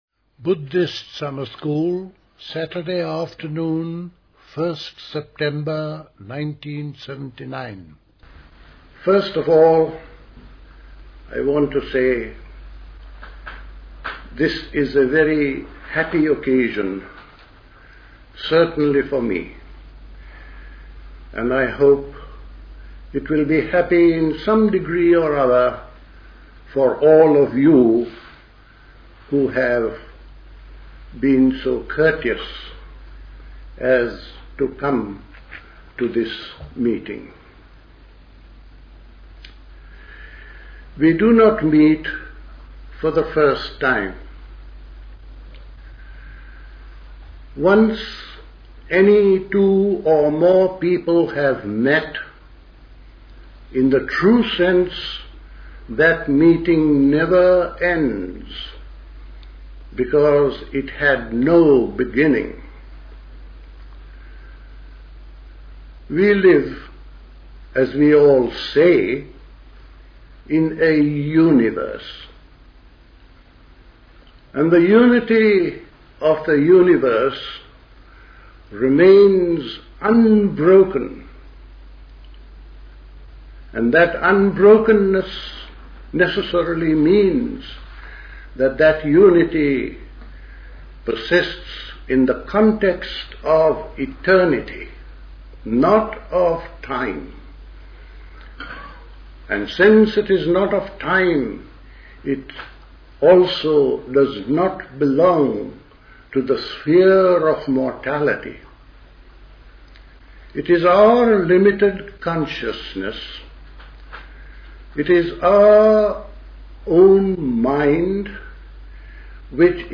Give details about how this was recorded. at High Leigh Conference Centre, Hoddesdon, Hertfordshire on 1st September 1979